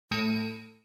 windows error.mp3